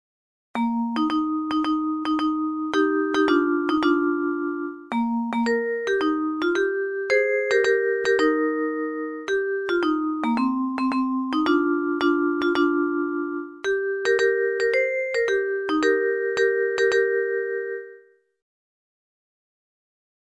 ﾋﾞﾌﾞﾗﾌｫﾝﾊﾓ